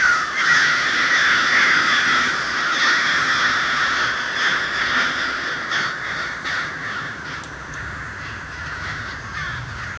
Crows flying out of the bushes
crows-flying-out-of-the-n5olyjan.wav